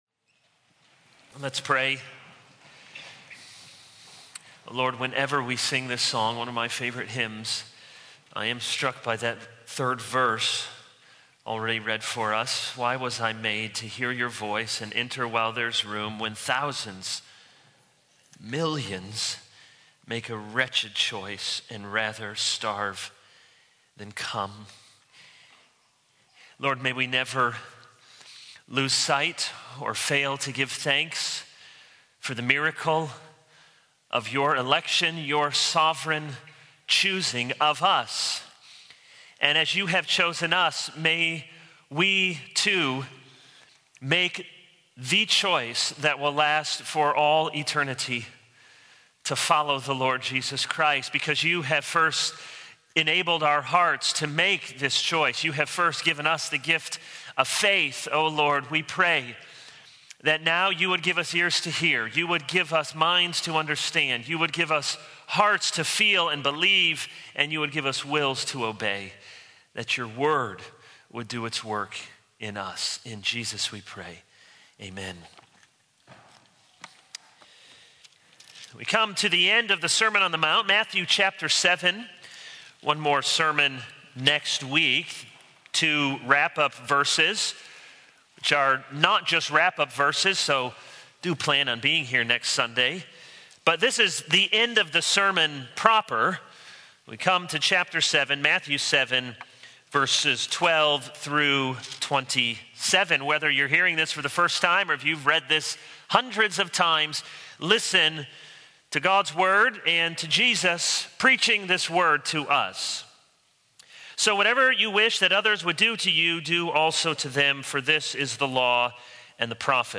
All Sermons Hosea 0:00 / Download Copied!